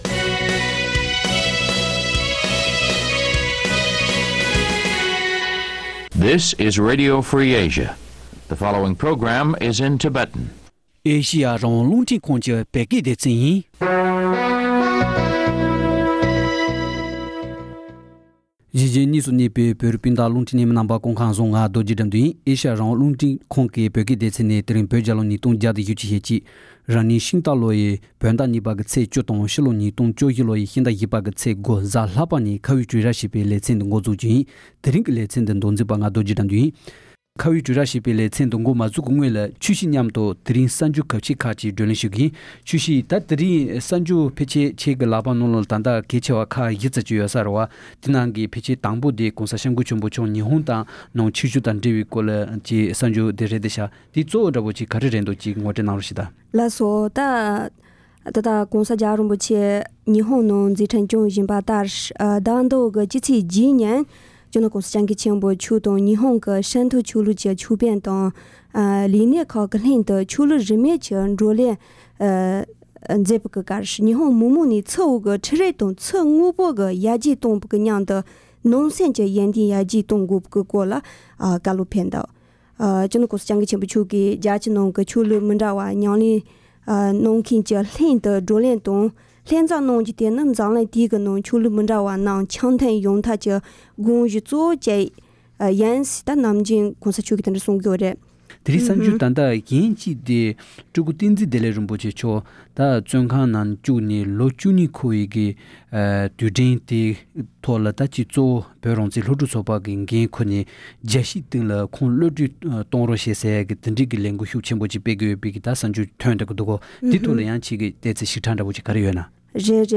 ༄༅། །བཀའ་བློན་ཁྲི་ཟུར་འཇུ་ཆེན་ཐུབ་བསྟན་རྣམ་རྒྱལ་མཆོག་གི་སྐུ་ཚེའི་ལོ་རྒྱུས་དང་འབྲེལ་བའི་ཉེ་རབས་བོད་ཀྱི་བྱུང་བ་བརྗོད་པའི་ལོ་རྒྱུས་གྱི་དཔེ་ཚོགས་པར་དེབ་གྲངས་༢༢པར་སྐྲུན་ལེགས་གྲུབ་ཟིན་ཡོད་པ་དང་། དེས་མ་འོངས་བོད་ཀྱི་ལོ་རྒྱུས་དང་སྤྱི་ཚོགས་ནང་ལེགས་སྐྱེས་དང་བག་ཆགས་ཇི་ཙམ་འཇོག་ཐུབ་མིན་གྱི་སྐོར་འབྲེལ་ཡོད་མི་སྣ་དང་གནས་འདྲི་ཞུས་པ་ཞིག་གསན་རོགས་གནང་།།